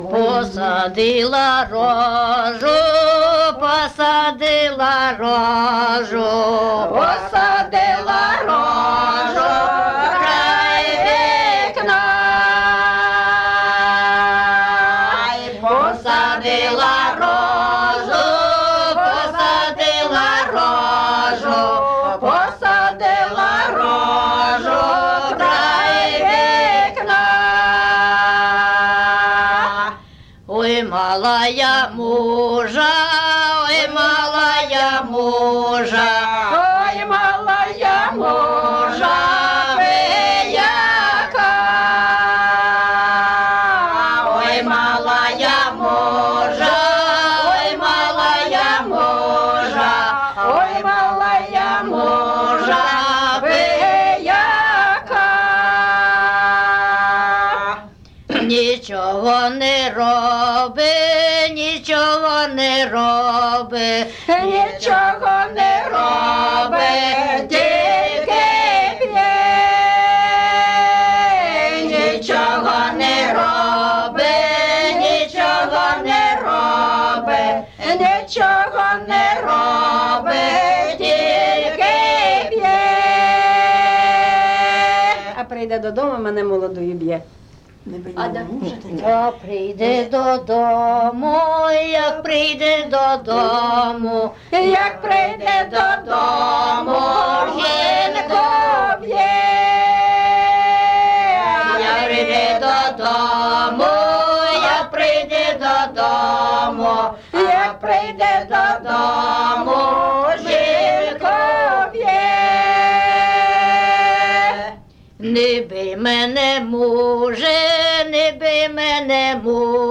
ЖанрПісні з особистого та родинного життя
Місце записус. Ізюмське, Борівський район, Харківська обл., Україна, Слобожанщина